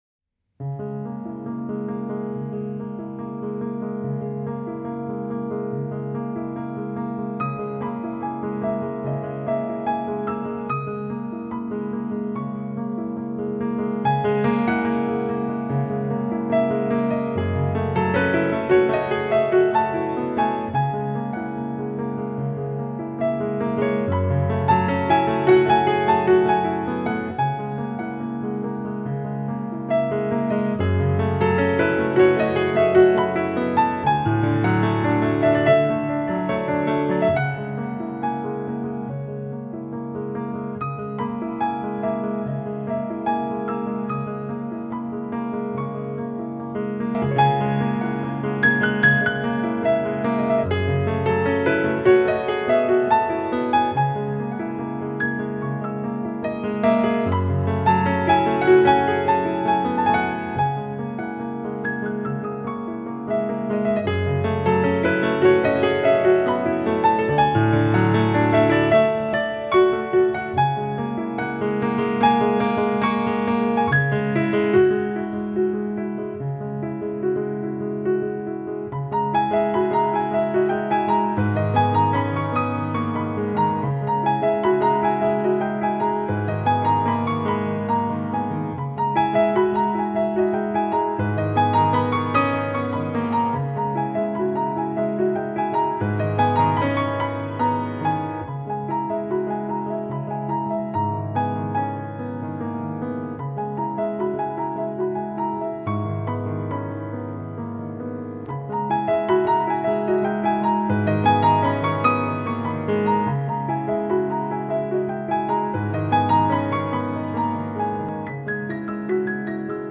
Silent Film Musician